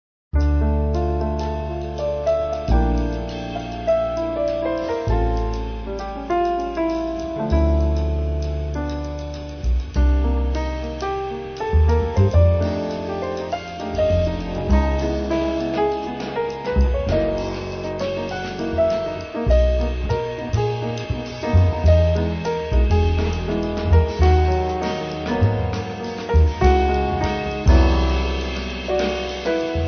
pianoforte
basso
batteria